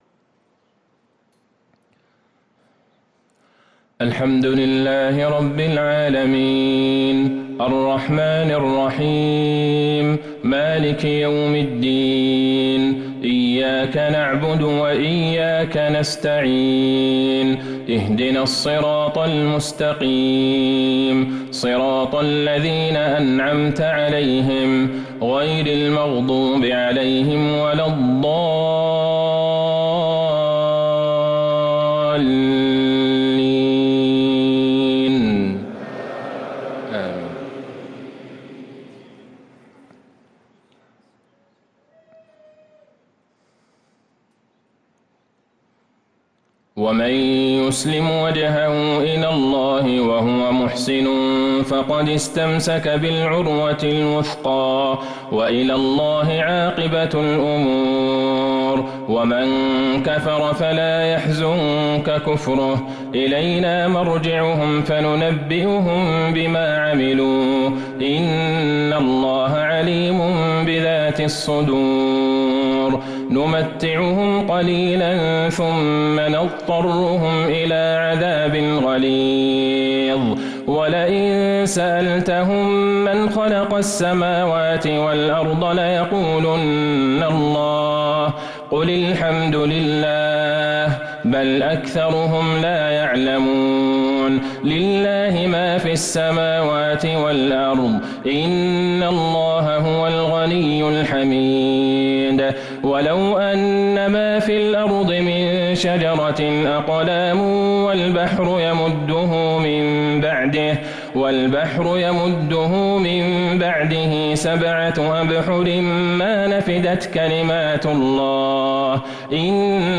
صلاة العشاء للقارئ عبدالله البعيجان 24 محرم 1445 هـ